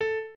piano6_17.ogg